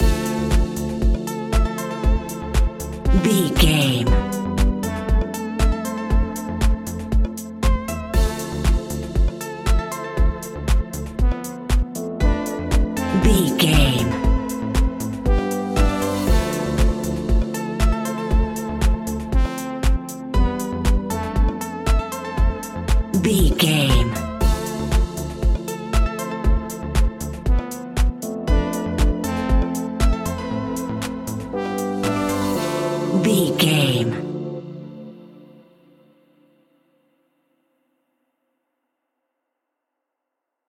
Aeolian/Minor
groovy
peaceful
smooth
drum machine
synthesiser
electro house
instrumentals
synth leads
synth bass